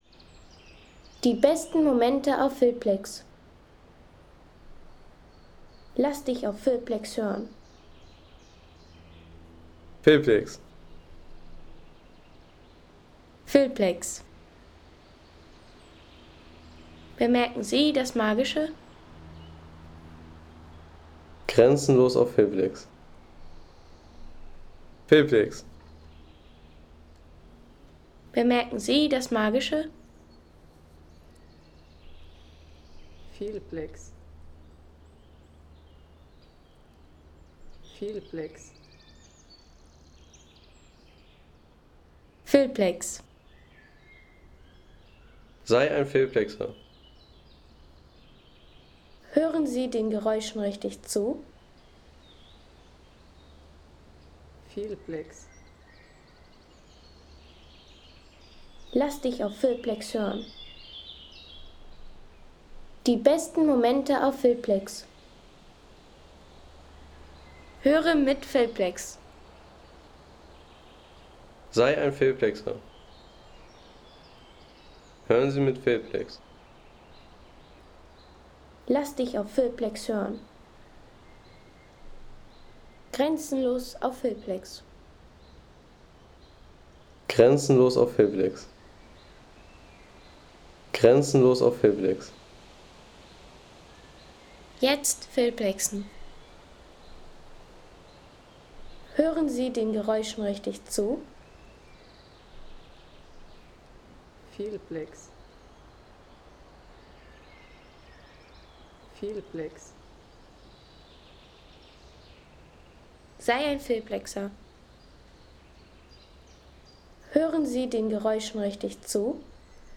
Authentische Naturatmosphäre inspiriert von den Nordoer Binnendünen in Schleswig-Holstein.
Eine offene und ruhige Landschaftskulisse, inspiriert von den Nordoer Binnendünen, für Filme, Dokus, Reisevideos und Sound-Postkarten.